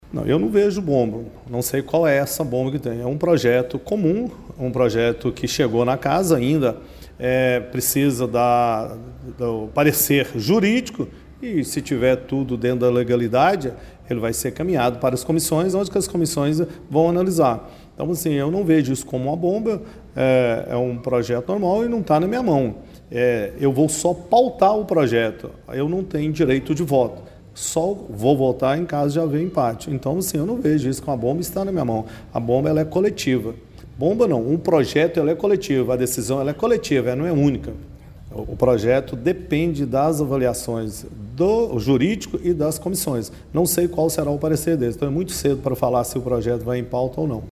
O Portal GRNEWS acompanhou a reunião ordinária da Câmara Municipal de Pará de Minas nesta terça-feira, 03 de dezembro.